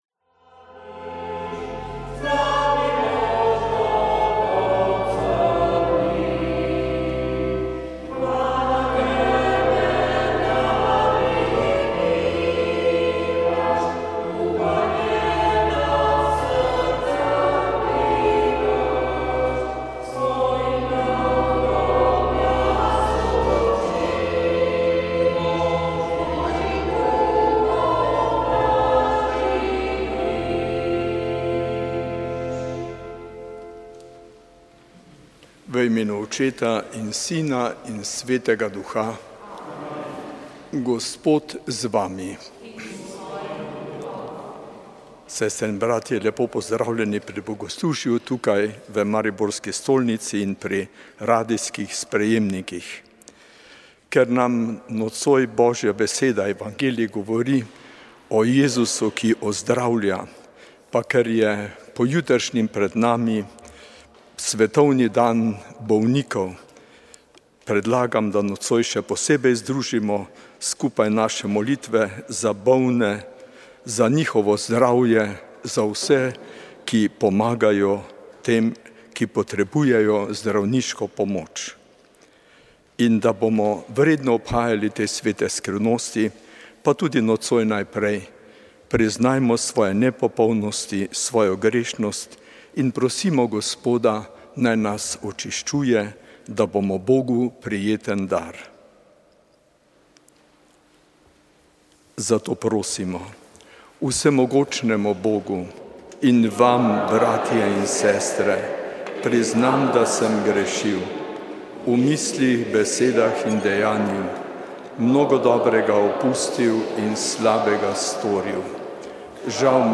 Sveta maša
Sv. maša iz župnije Marijinega oznanjenja v Ljubljani dne 29. 6.